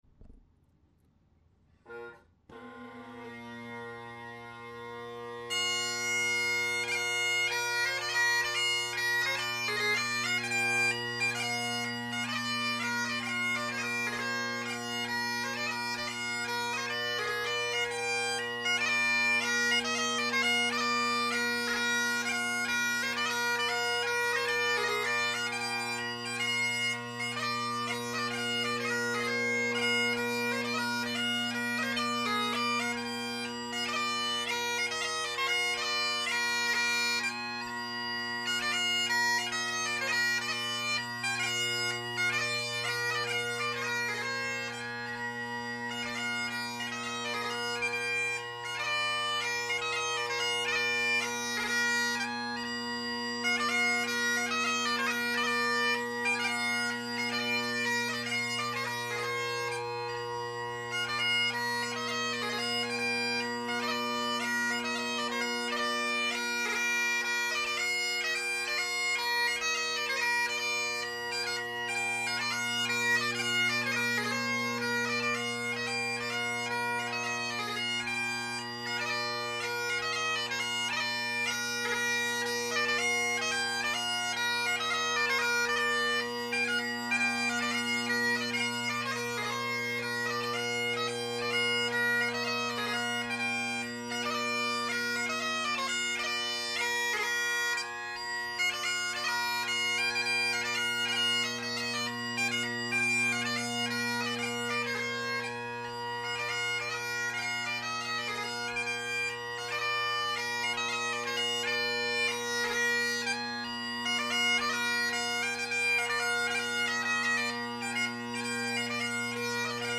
Great Highland Bagpipe Solo
1950’s Hendersons – Selbie drone reeds – Colin Kyo delrin chanter – heavily carved Apps G3 chanter reed
Be patient, each recording is unmodified from the recorder at 160 KB/s using mp3, there are a couple seconds before I strike in after I press record.